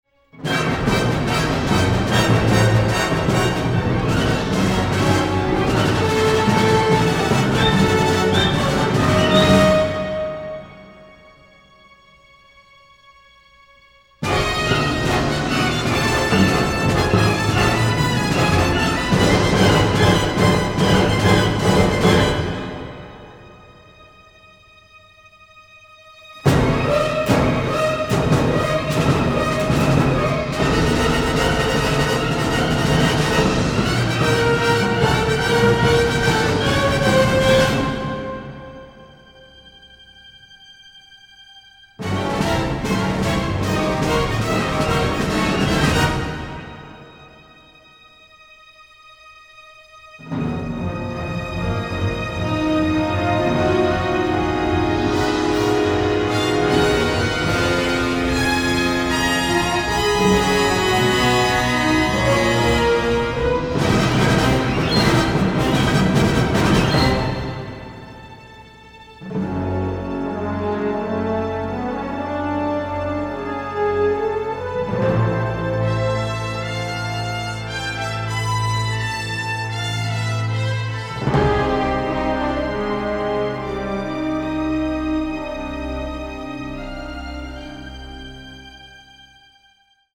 powerhouse, original orchestral material